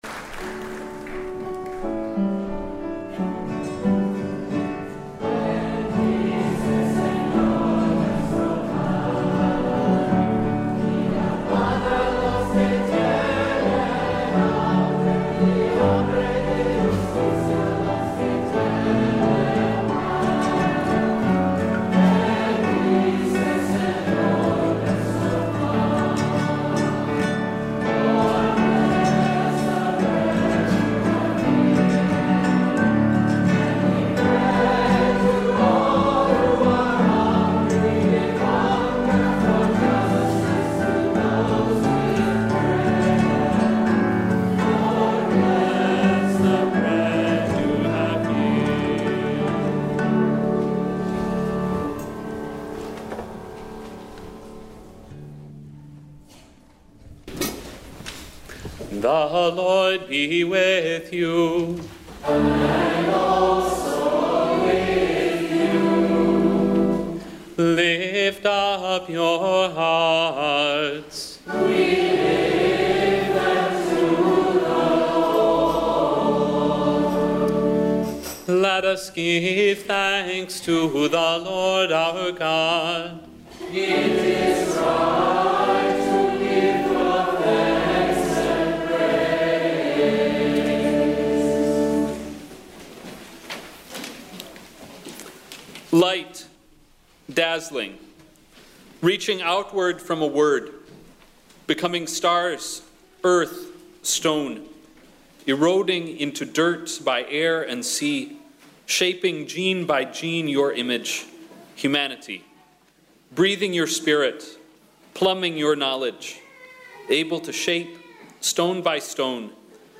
Festgottesdienst
Great Prayer of Thanksgiving 3.
Eucharistic Prayer 5.
Brass band closing